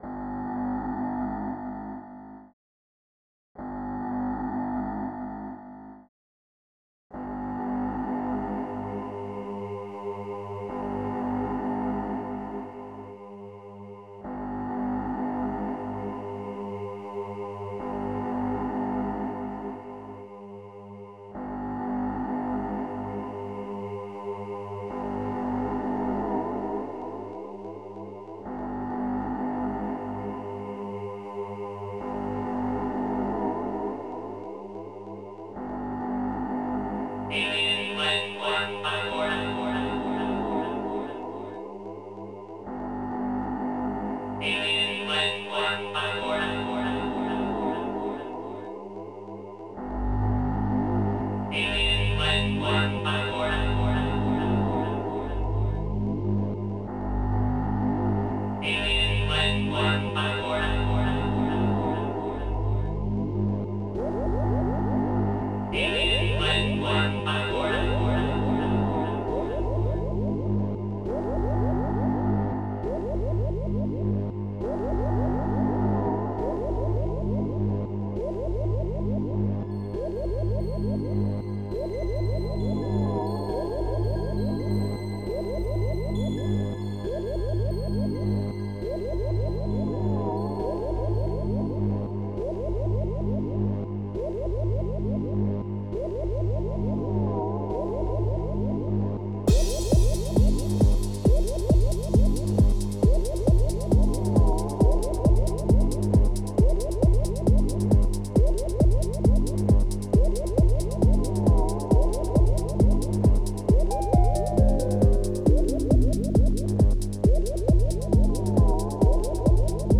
xm (FastTracker 2 v1.04)
Just Another Trance